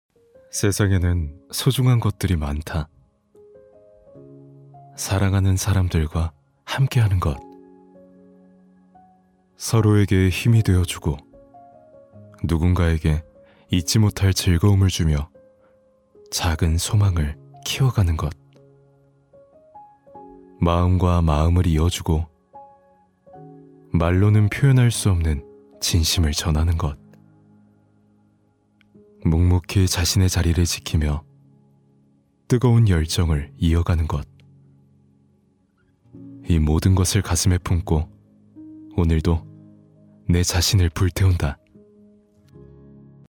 성우샘플
차분/편안